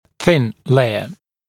[θɪn ‘leɪə][син ‘лэйэ]тонкий слой